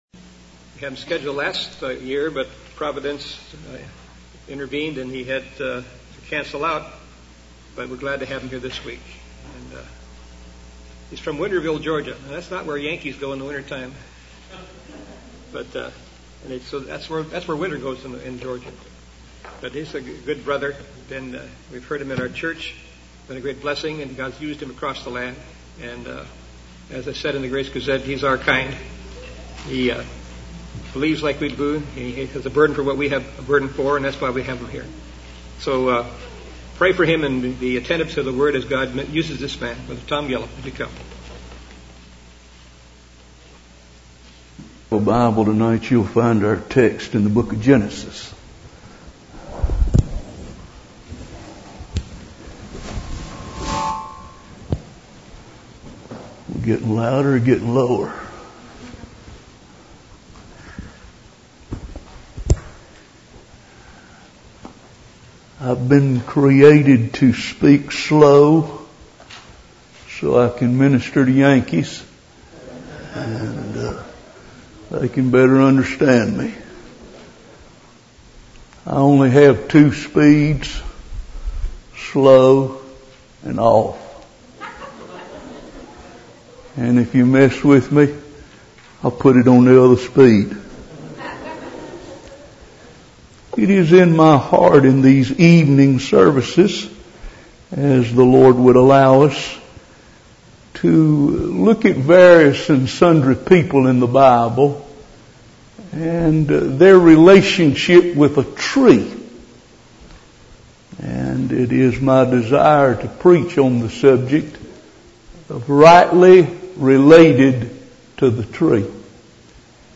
Series: 2011 July Conference Session: Evening Session